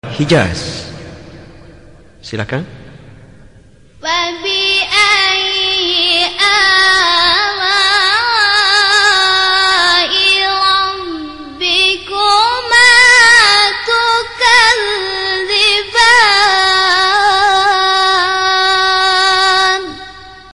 حجاز اصلی قرار4.mp3
حجاز-اصلی-قرار4.mp3